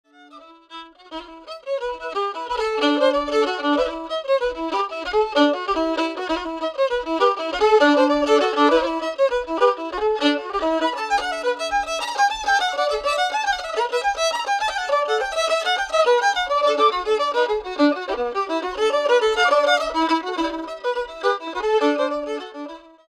21 Cape Bretoners playing tunes
F jig